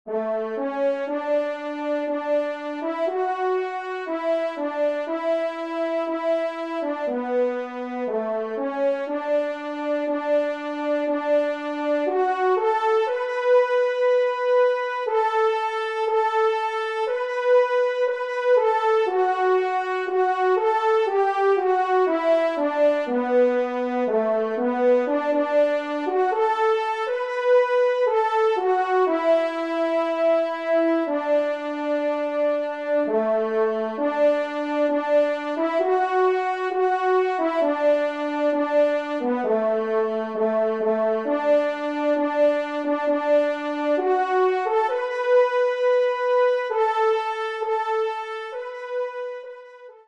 Auteur : Chant Traditionnel Écossais
1e Trompe